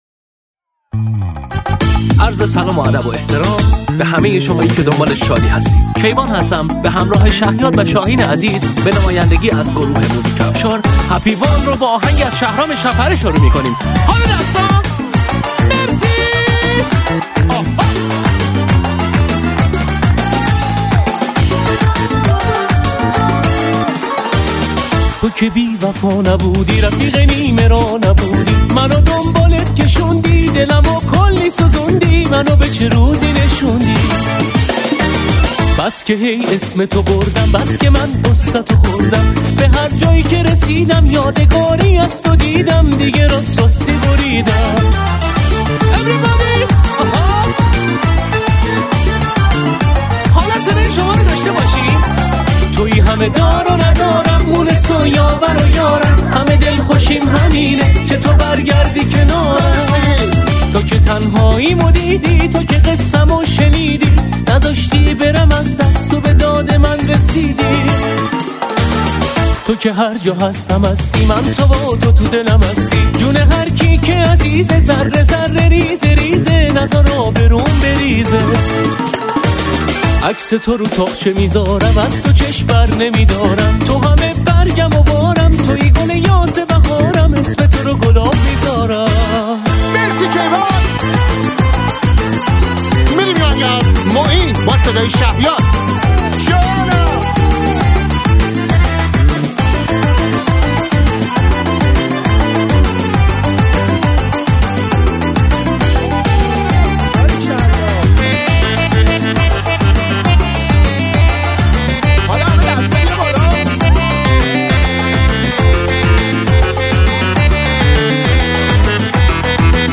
آهنگ رقص جمعی عروسی